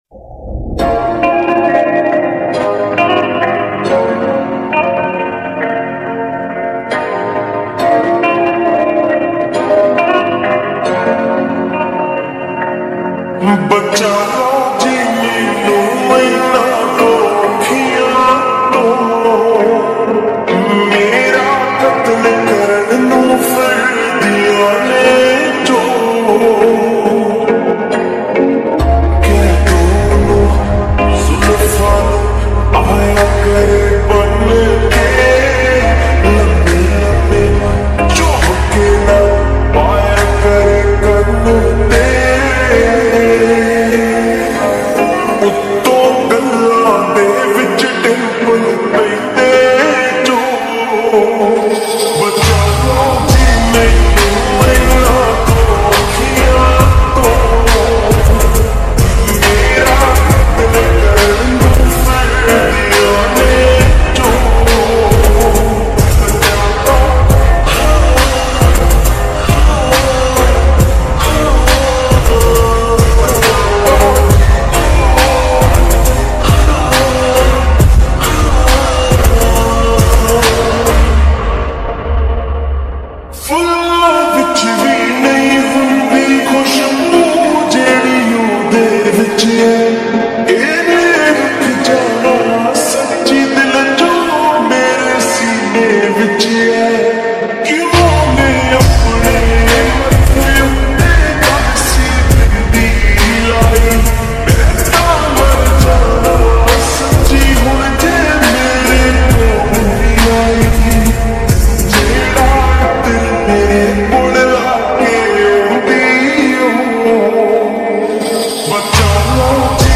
Slowed+Reverb